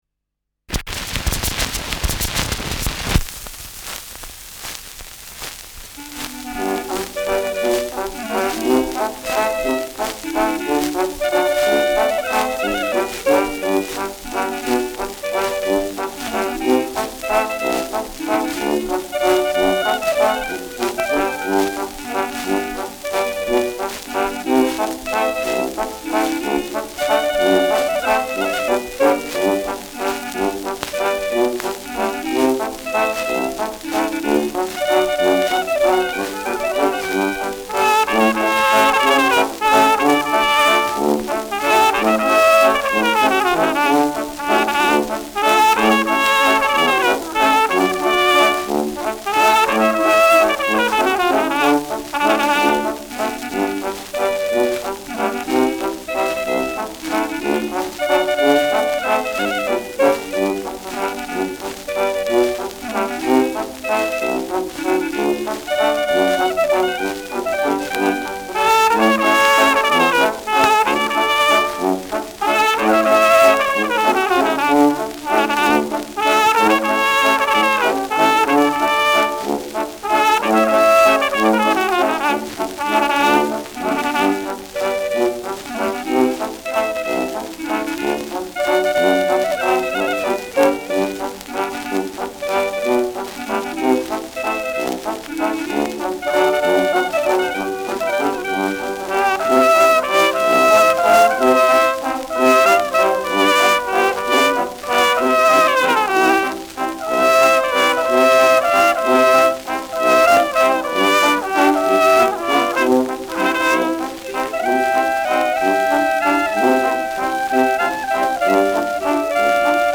Schellackplatte
Durchgehend leichtes Nadelgeräusch durch Tonarmspringen : Leiern : Vereinzelt leichtes Knacken